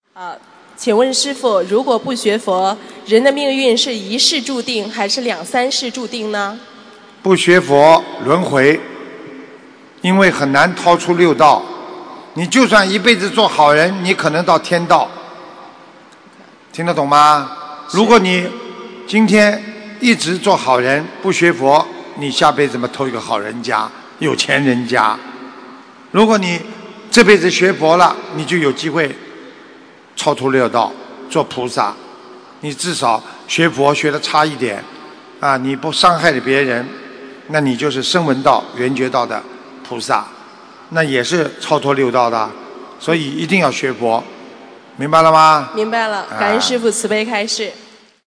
不学佛难出轮回，学佛有机会脱离轮回┃弟子提问 师父回答 - 2017 - 心如菩提 - Powered by Discuz!